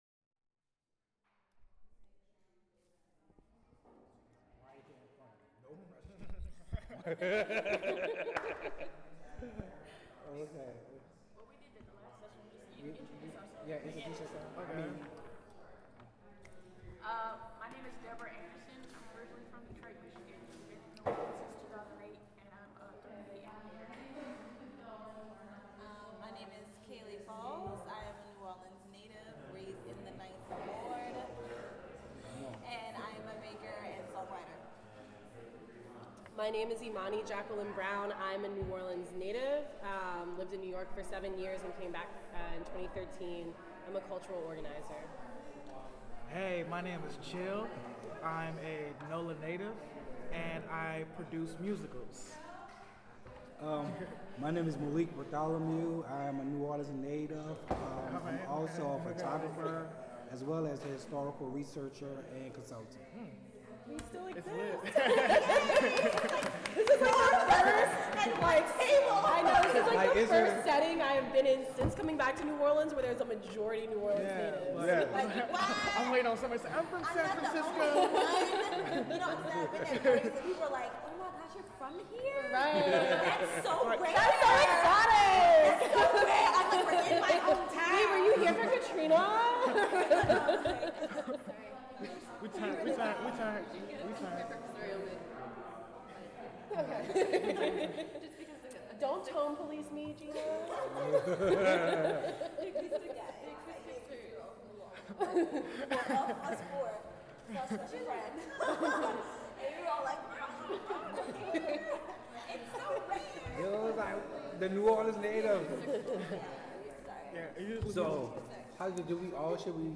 sound recording-nonmusical
oral history